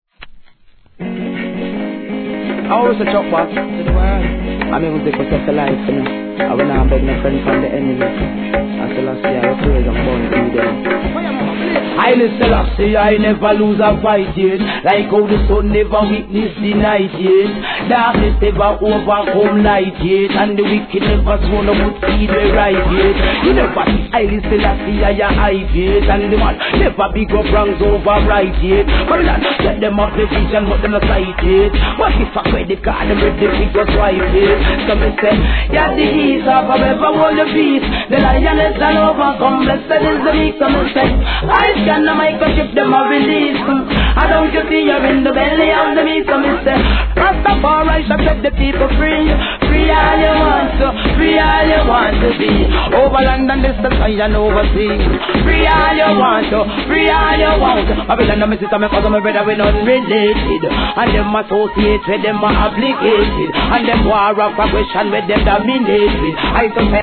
REGGAE
2008年、洒落オツなビンギ調のアレンジでWICKED DeeJay STYLE!!